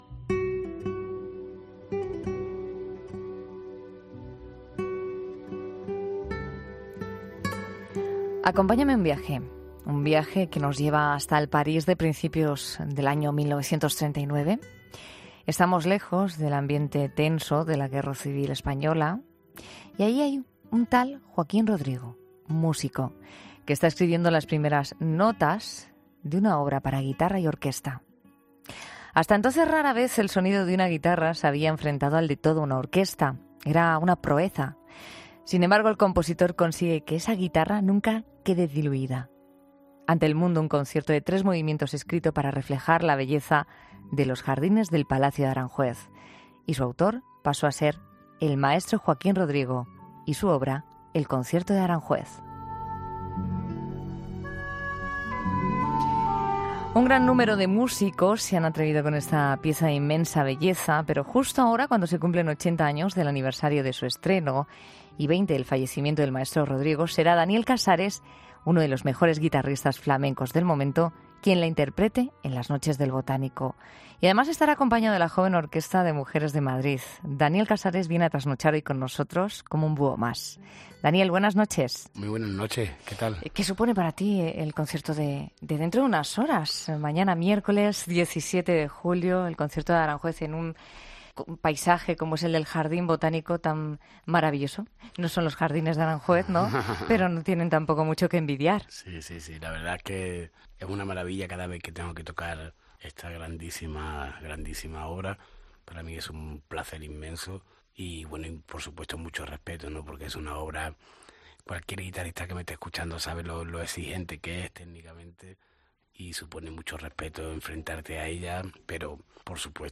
El guitarrista flamenco